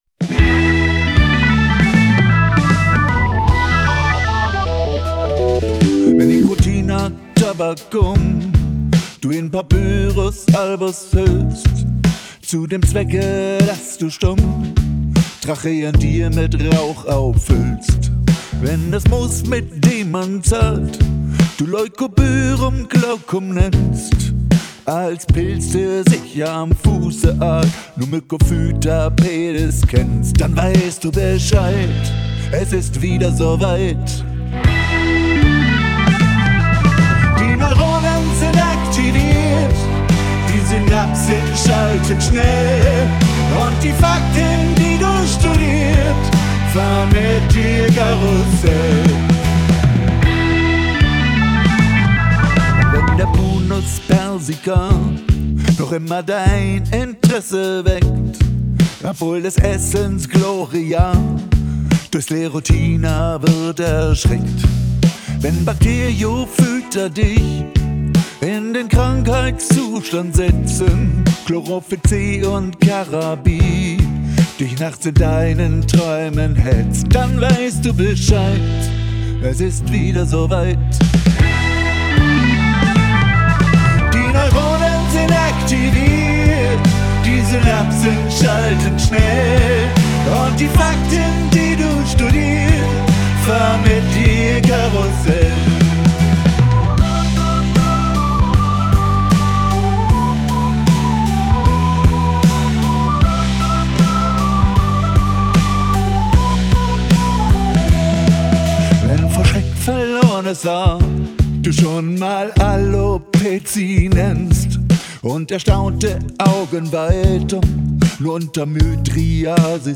[pop]